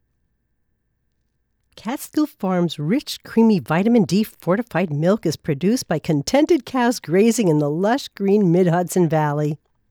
I’d LOVE a review of my raw wav file.